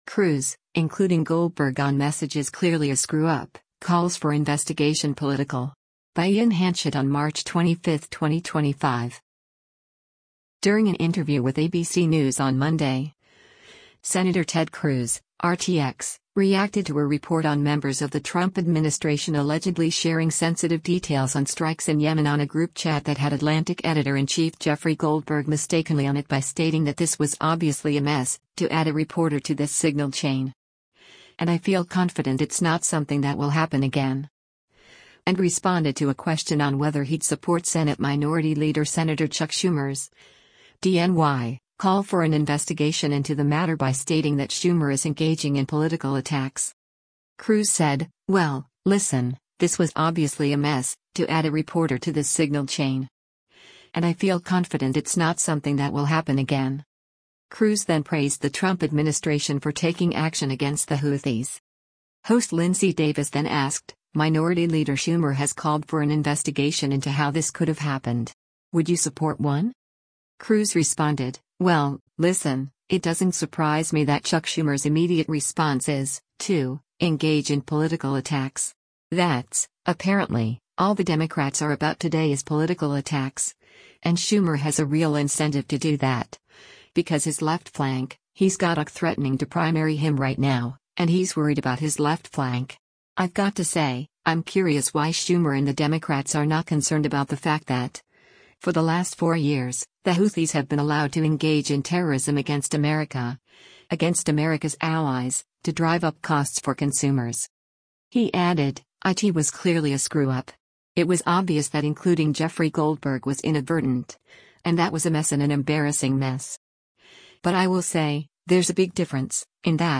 During an interview with ABC News on Monday, Sen. Ted Cruz (R-TX) reacted to a report on members of the Trump administration allegedly sharing sensitive details on strikes in Yemen on a group chat that had Atlantic Editor-in-Chief Jeffrey Goldberg mistakenly on it by stating that “this was obviously a mess, to add a reporter to this Signal chain. And I feel confident it’s not something that will happen again.”